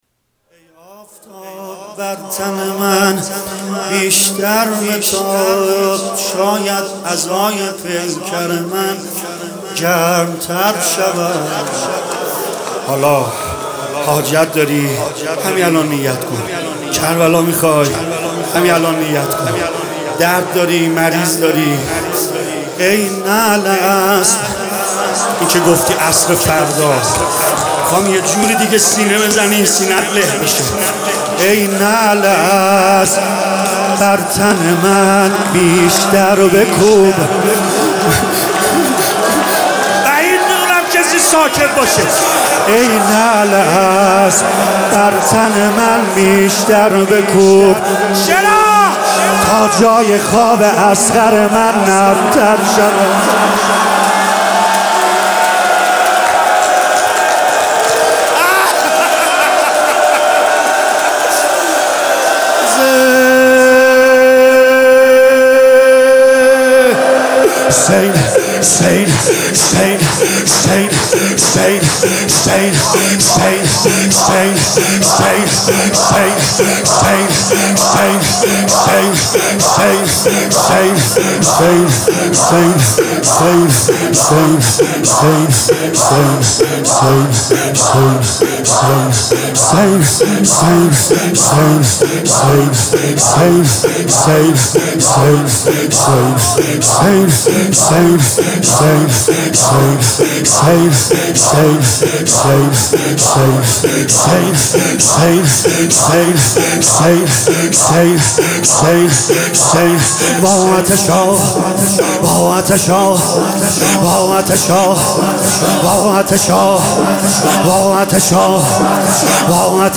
تولید شده: هیئت فدائیان حسین (ع) اصفهان سیدرضا نریمانی